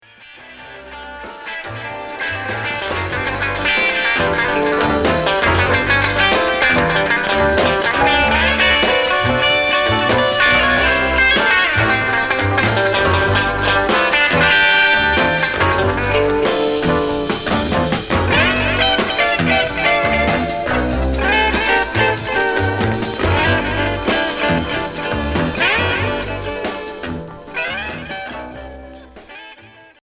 Blues beat